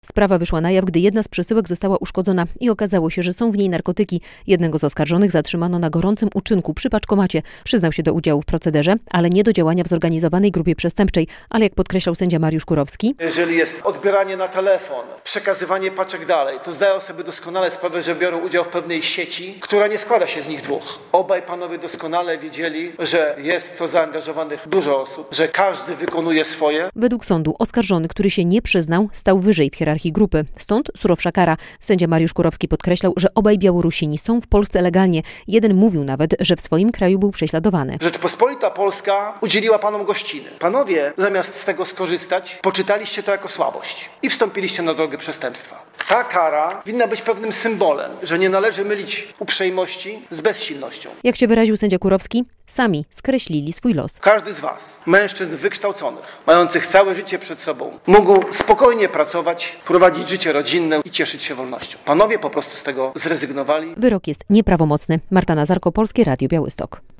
Dwóch Białorusinów skazanych za handel narkotykami przez Internet - relacja